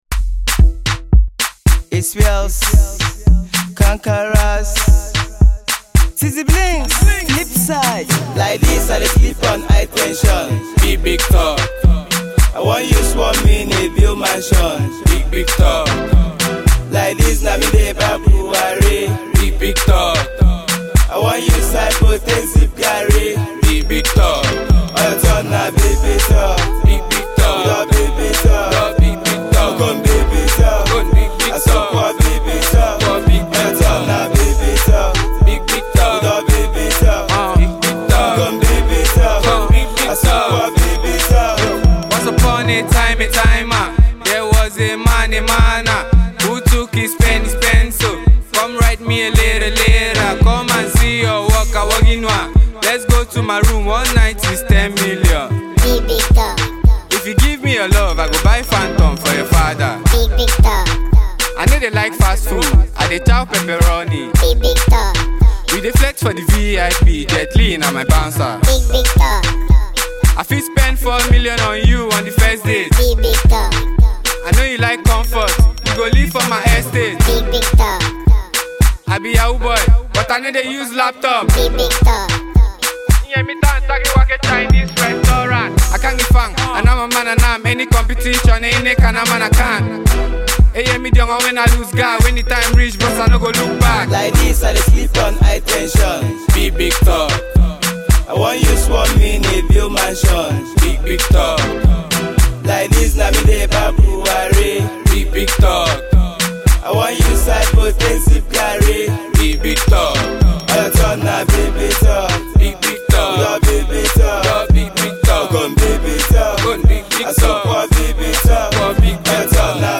club banging hit